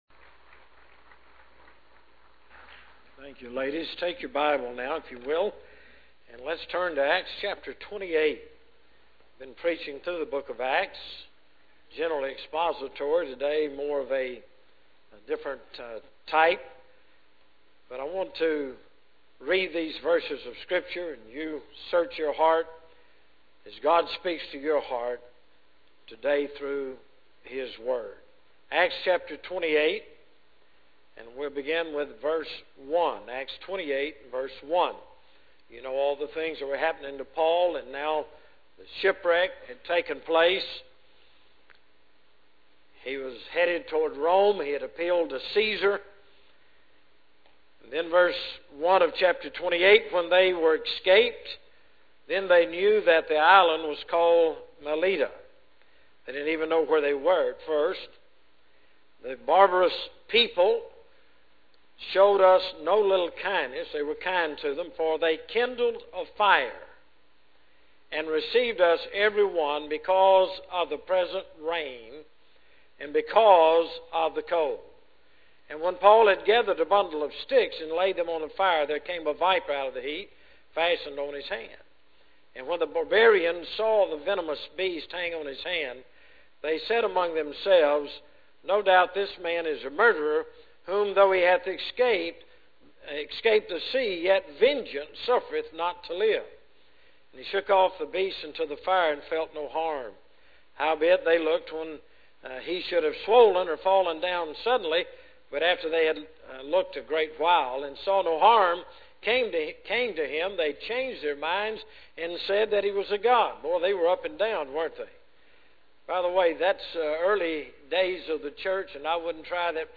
General Worship Services